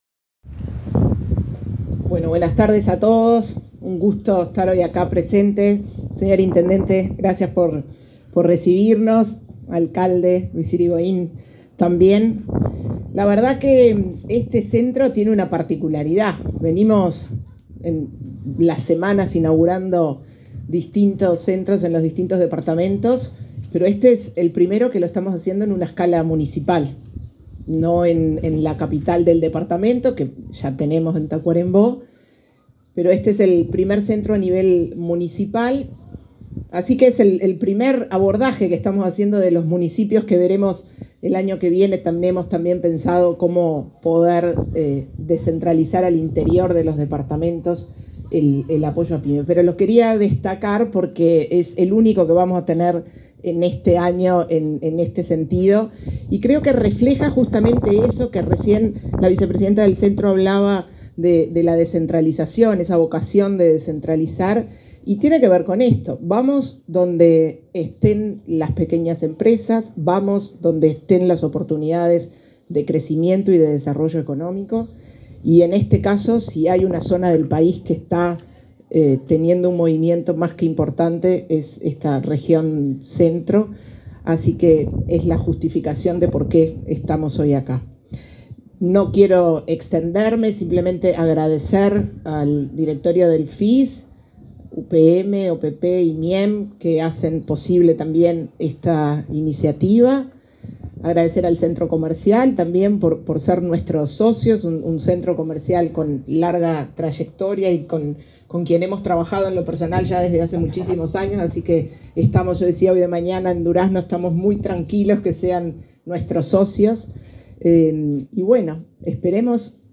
Conferencia de prensa por la inauguración de Centro Pyme en Paso de los Toros
El 28 de octubre, ANDE inauguró el Centro Pyme de Paso de los Toros, con el objetivo de ofrecer herramientas para fomentar el crecimiento de las micro, pequeñas y medianas empresas, así como de las personas emprendedoras. Participaron el subsecretario del Ministerio de Industria, Energía y Minería, Walter Verri; el director de la Oficina de Planeamiento y Presupuesto, Isaac Alfie, y la presidenta de ANDE, Cármen Sánchez, entre otras autoridades.